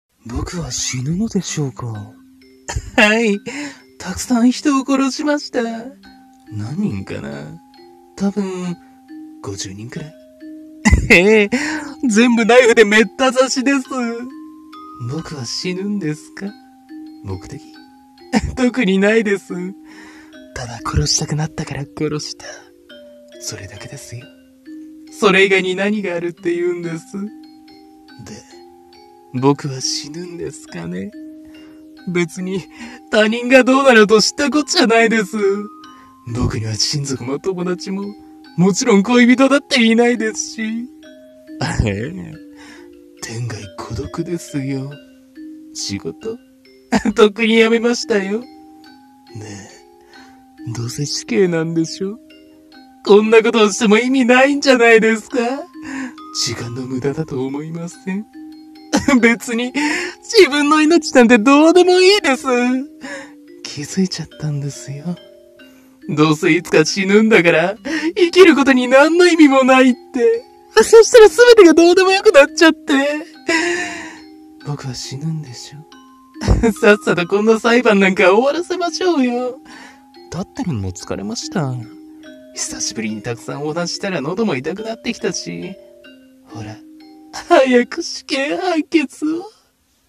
声劇「死刑裁判」